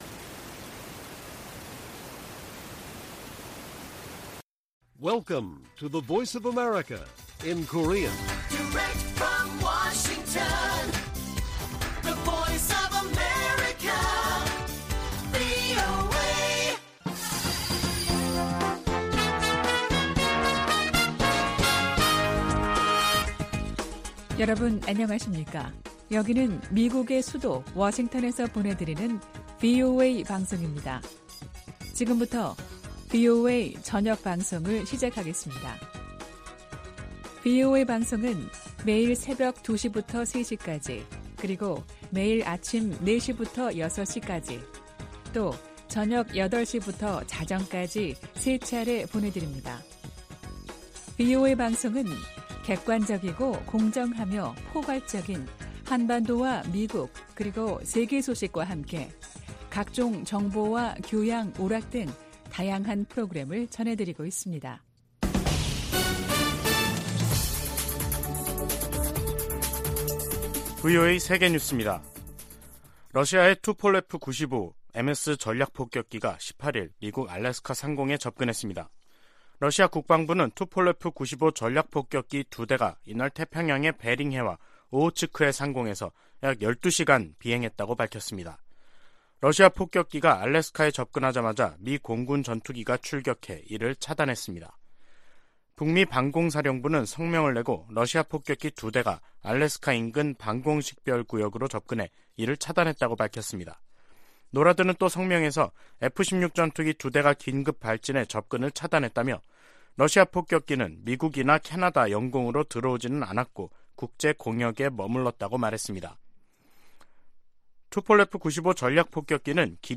VOA 한국어 간판 뉴스 프로그램 '뉴스 투데이', 2022년 10월 19일 1부 방송입니다. 북한이 18일 밤부터 19일 오후까지 동해와 서해 완충구역으로 350여 발의 포병 사격을 가하면서 또 다시 9.19 남북군사합의를 위반했습니다. 미 국무부는 북한의 포 사격에 대해 모든 도발적 행동을 중단할 것을 촉구했습니다. 미국 헤리티지재단은 '2023 미국 군사력 지수' 보고서에서 북한의 핵을 가장 큰 군사적 위협 중 하나로 꼽았습니다.